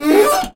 carSuspension2.ogg